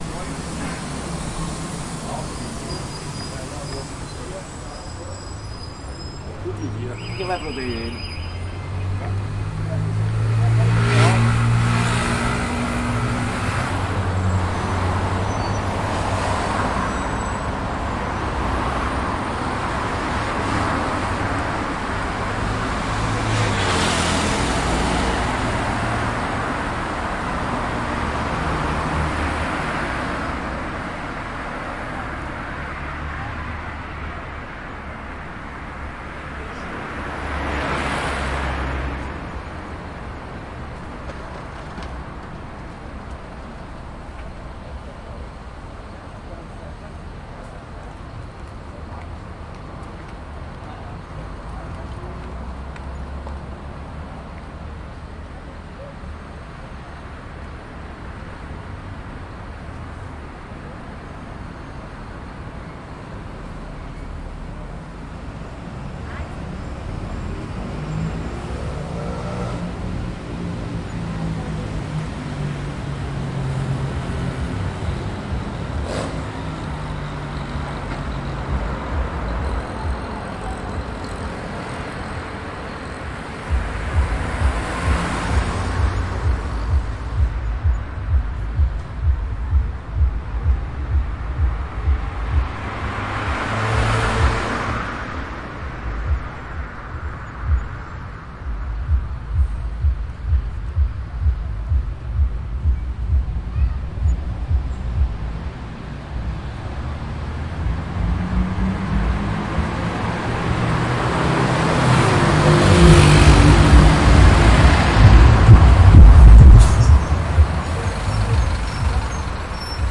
描述：白天在FozdoIguaçu的Av JK的交通
Tag: 总线 交通 街道 汽车 城市